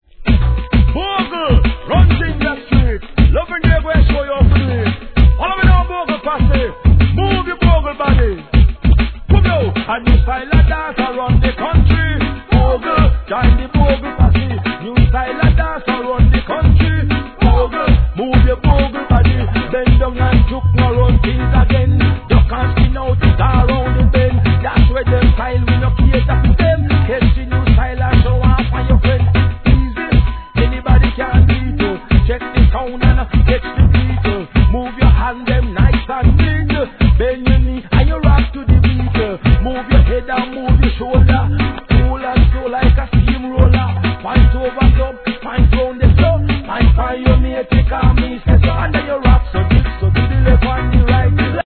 REGGAE
ダンス・ネタ!